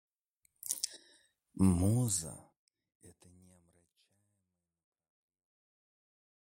Аудиокнига Посвящение Ах | Библиотека аудиокниг